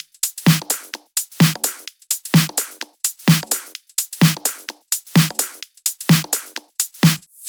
VFH3 128BPM Wobble House Kit
VFH3 128BPM Wobble House Kit 5.wav